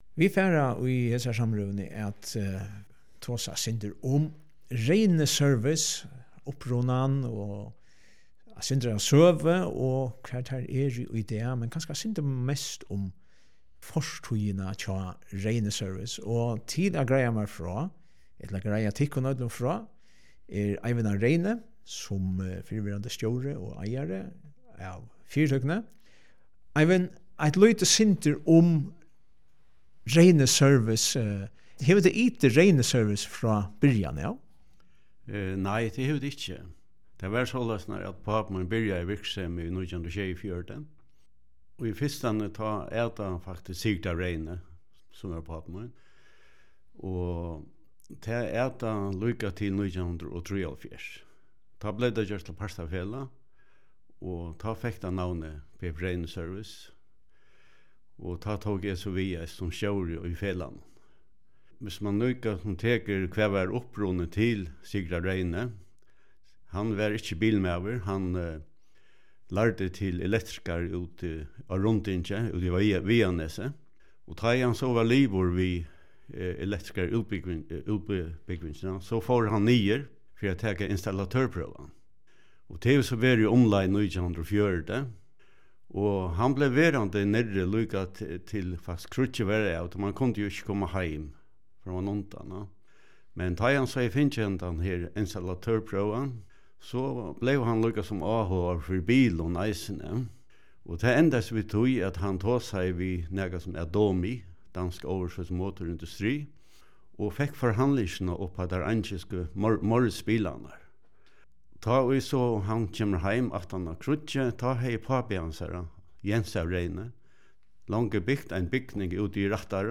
ger samrøðuna